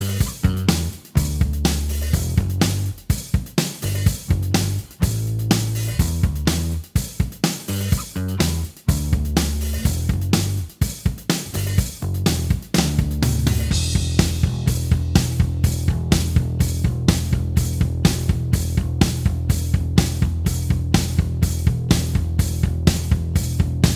Minus All Guitars Rock 3:26 Buy £1.50